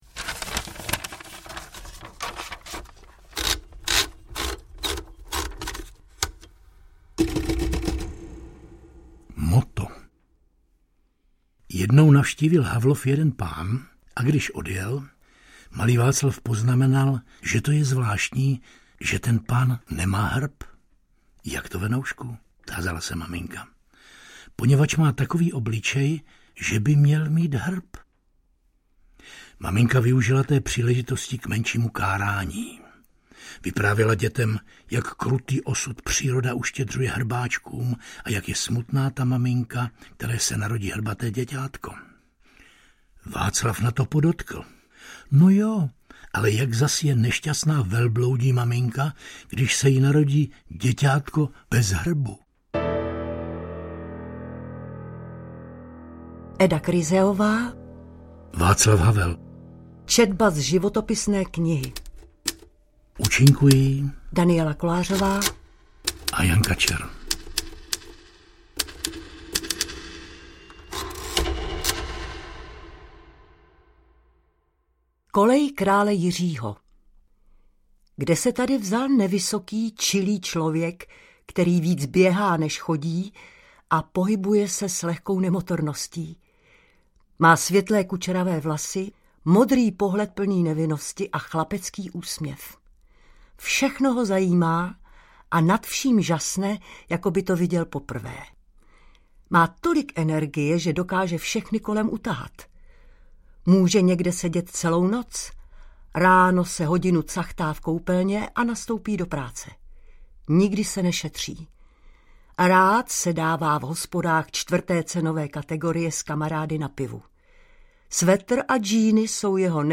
Václav Havel audiokniha
Ukázka z knihy
• InterpretDaniela Kolářová, Jan Kačer